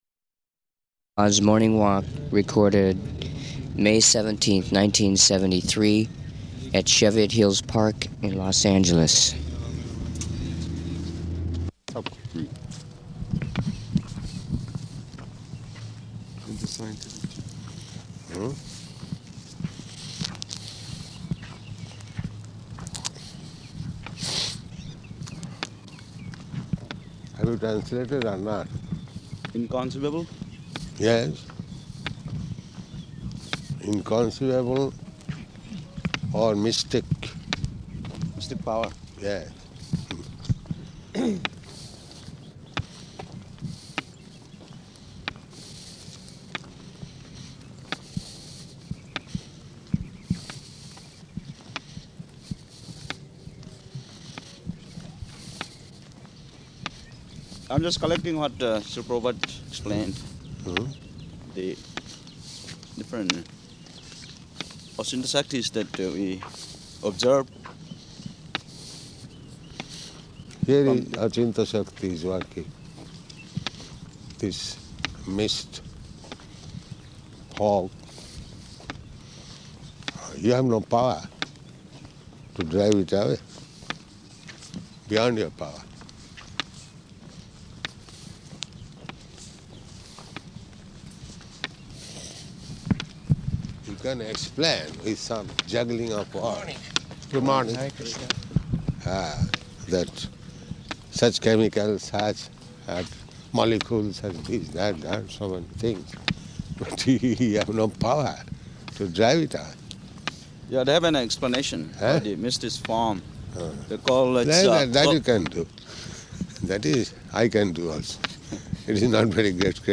Morning Walk At Cheviot Hills Golf Course